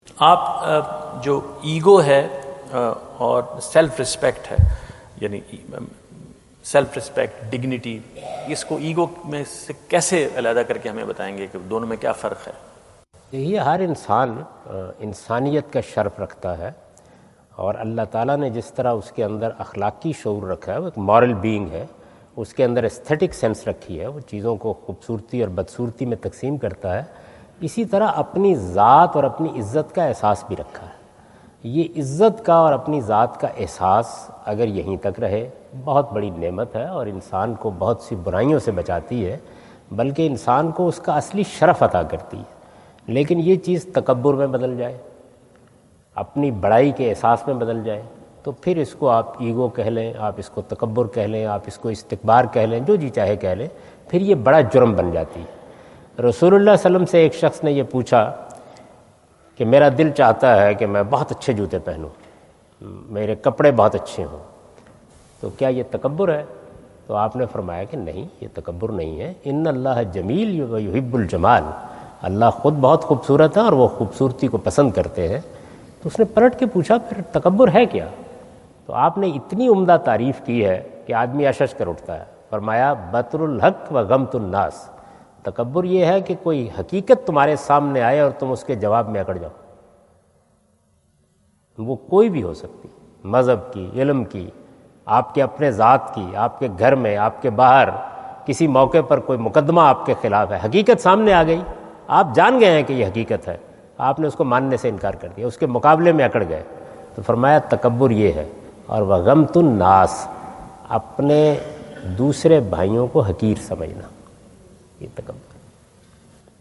Javed Ahmad Ghamidi answer the question about "Difference between ‘Self Respect’ and ‘Ego’?" During his US visit at Wentz Concert Hall, Chicago on September 23,2017.
جاوید احمد غامدی اپنے دورہ امریکہ2017 کے دوران شکاگو میں "Ego اور Self respect میں کیا فرق ہے؟" سے متعلق ایک سوال کا جواب دے رہے ہیں۔